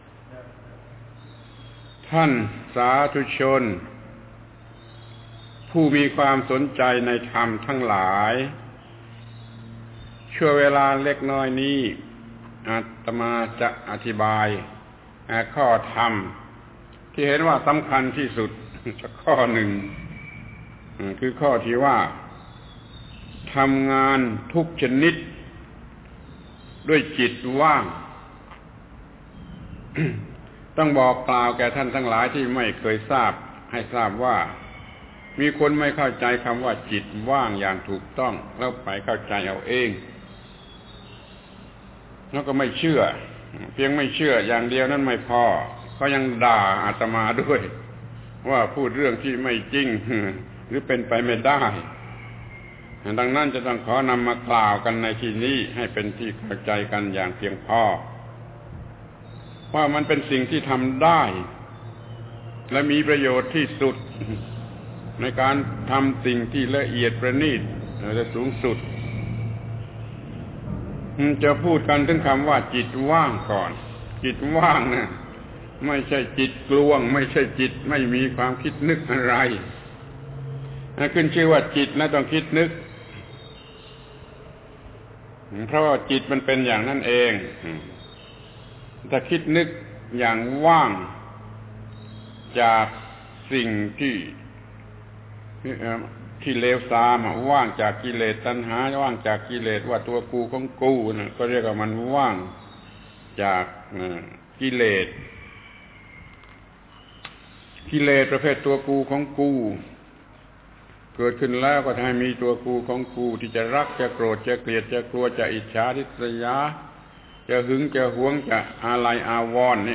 ปาฐกถาธรรม รายการพุทธธรรมนำสุข ครั้งที่ ๒ ครั้ง ๗ ทำงานทุกชนิดด้วยจิตว่าง